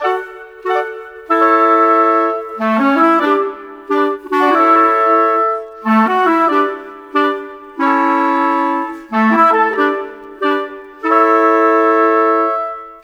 Rock-Pop 06 Winds 01.wav